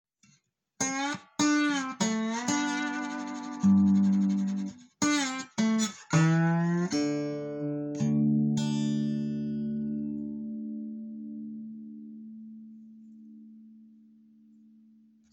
Old jazzbox is a fine lap steel guitar now
The guitar looks great and the slide sounds full and authentic.